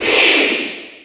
Category:Crowd cheers (SSB4) You cannot overwrite this file.
Peach_Cheer_German_SSB4.ogg